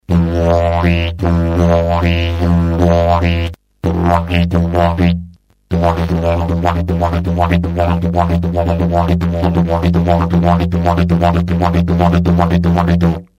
Диджериду
Ритмическое упражнение ''Триоль''
Фигура будет следующая: "тУ-а-ки", "тА-у-ки" или "кА-у-ки".
tu-a-ki--tu-a-ki.mp3/